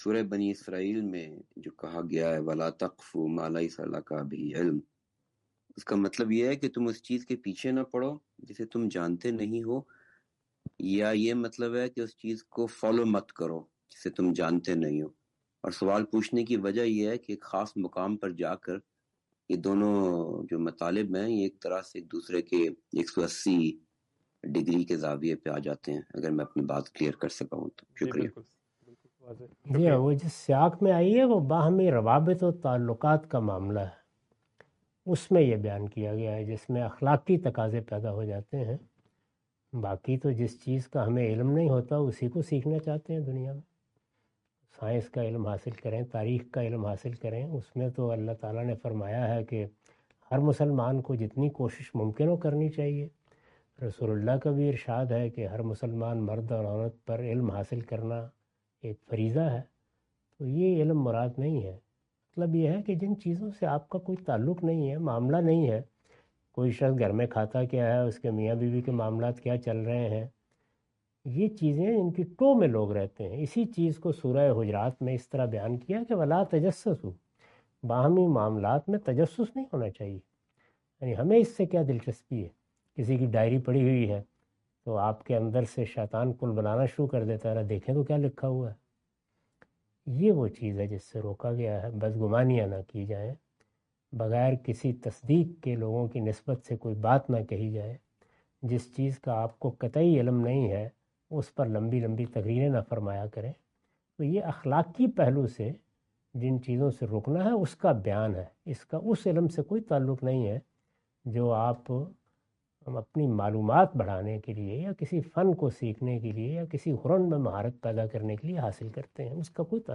Category: Reflections / Questions_Answers /
In this video, Mr Ghamidi answer the question about "What does the Quranic guidance mean "Follow not that of which you have no knowledge".
اس ویڈیو میں جناب جاوید احمد صاحب غامدی " قرآن مجید کی اس ہدایت کہ "اُس چیز کے پیچھے نہ پڑو جسے تم جانتے نہیں ہو" کا مطلب کیا ہے؟" سے متعلق سوال کا جواب دے رہے ہیں۔